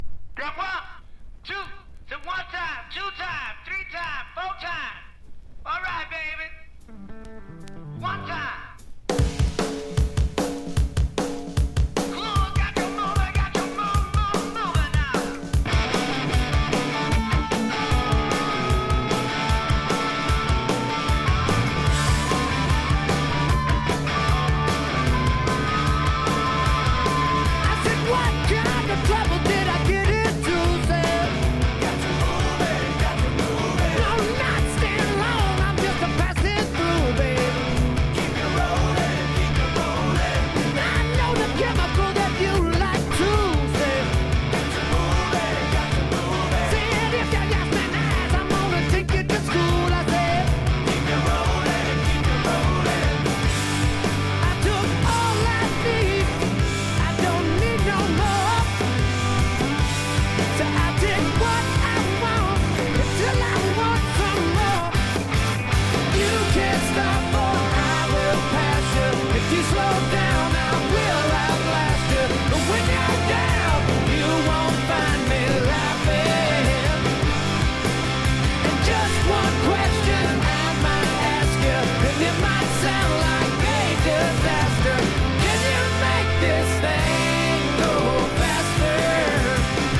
1. 90'S ROCK >
MIXTURE / LOUD / HR